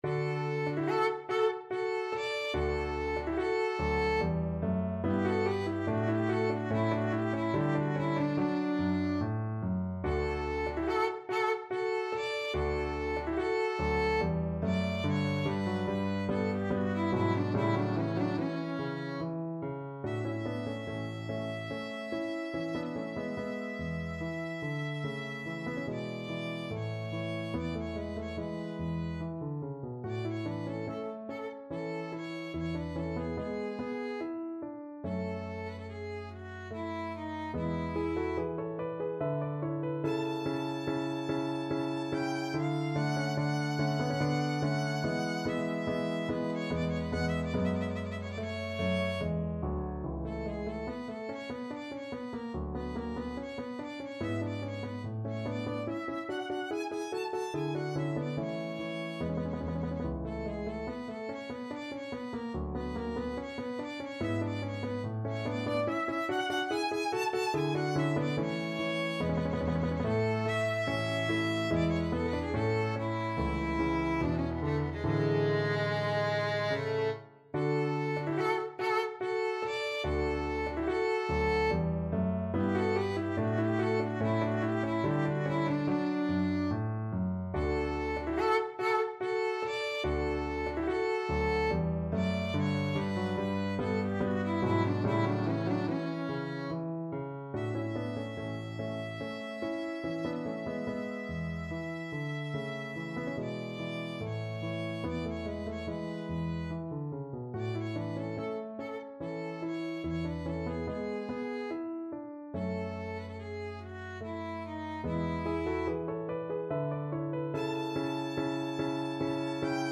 Play (or use space bar on your keyboard) Pause Music Playalong - Piano Accompaniment Playalong Band Accompaniment not yet available transpose reset tempo print settings full screen
Violin
Andante =72
3/4 (View more 3/4 Music)
D major (Sounding Pitch) (View more D major Music for Violin )
Classical (View more Classical Violin Music)